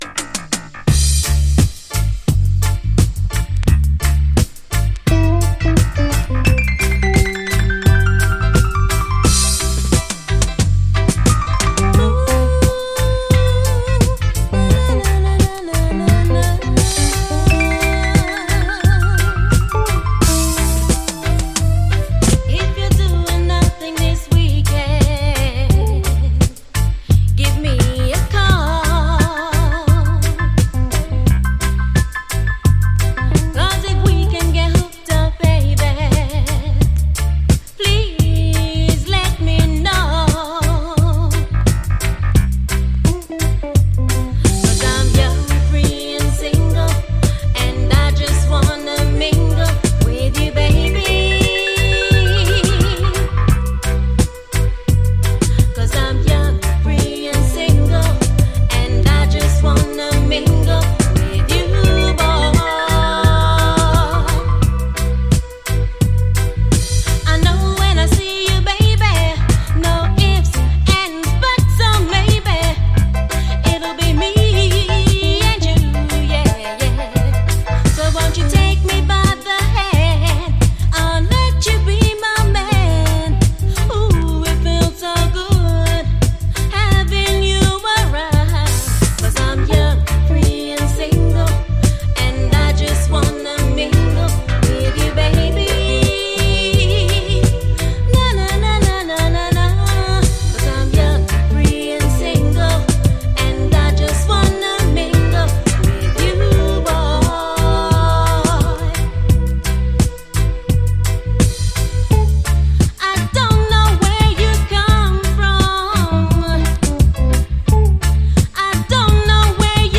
REGGAE